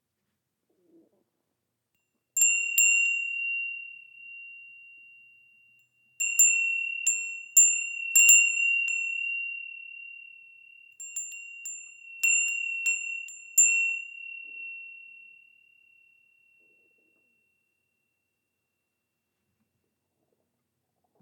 Temple Bell Wind Chime – NEW!
This windchime in the shape of a Zen bell is a blend of elegant look and serene sound, designed to evoke a sense of tranquility and mindfulness.
Hanging from a slender, sturdy cord, the bell sways gently in the breeze, producing a soft, melodic chime. The movement of the wind brings forth a peaceful, lingering sound that invites contemplation and relaxation.
Cast iron, made in Japan
Temple-Bell-Windchime.mp3